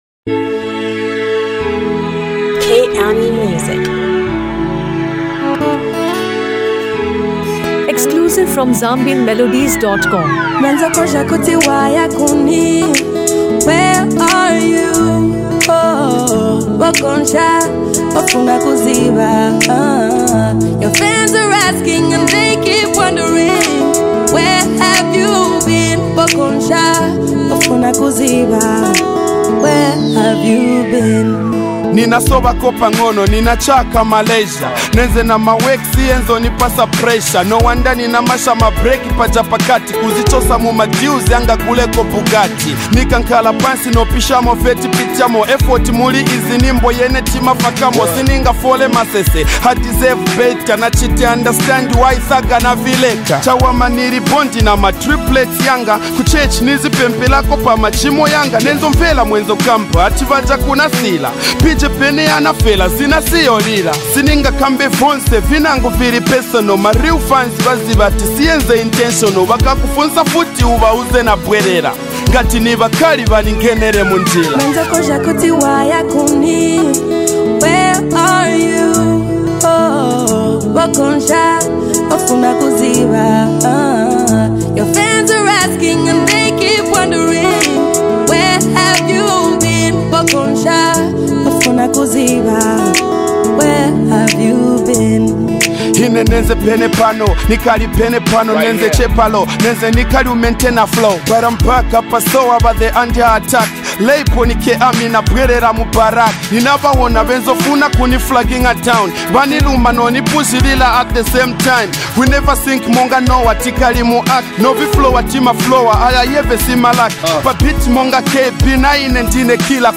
celebrated for her soulful and emotional vocals.
blends hip-hop, Afro-fusion, and soul
praised for its lyrical maturity and rich sound.